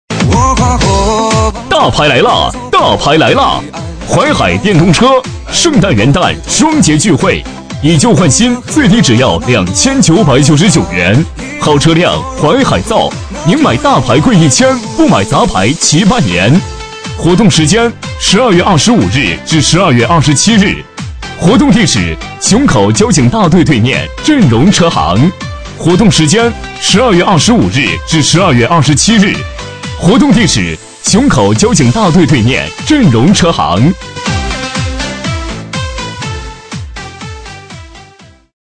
B类男10
【男10号促销】淮海电动车
【男10号促销】淮海电动车.mp3